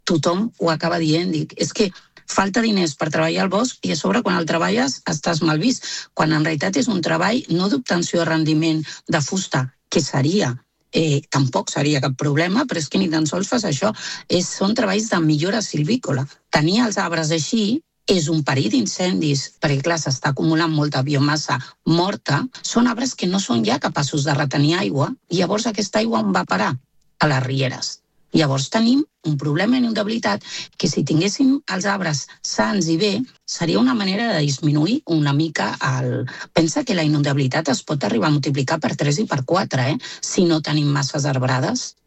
Segons ha explicat la consellera de Medi Natural del Consell Comarcal del Maresme, Marta Gómez, a l’entrevista de RCT, el problema de fons és l’excés de densitat forestal.